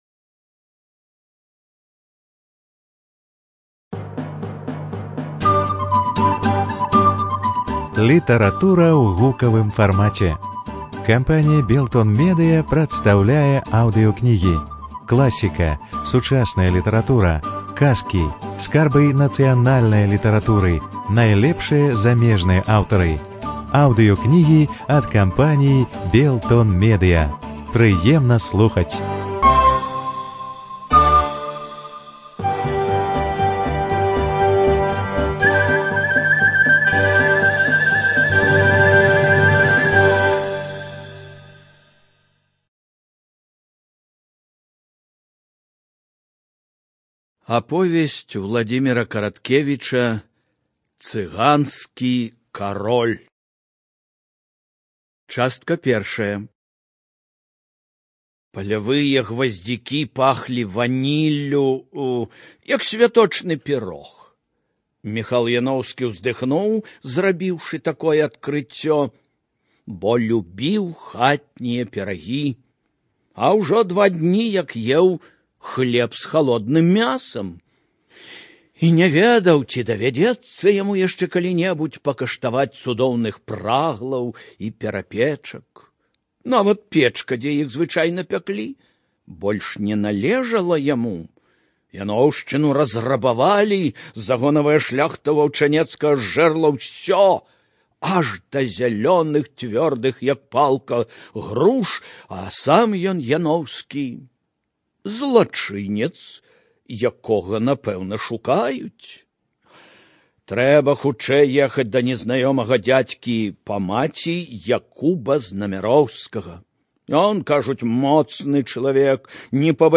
ЖанрКниги на языках народов Мира